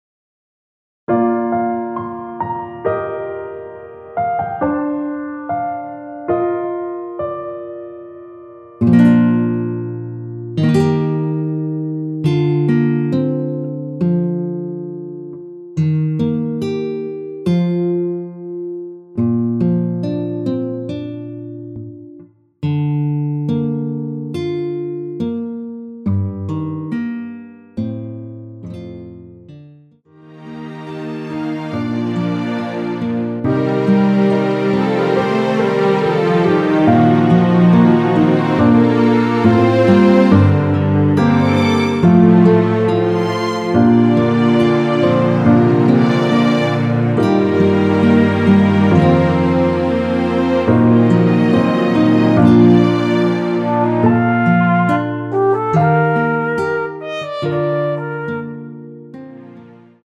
원키에서(+1)올린 MR입니다.
Bb
앞부분30초, 뒷부분30초씩 편집해서 올려 드리고 있습니다.
중간에 음이 끈어지고 다시 나오는 이유는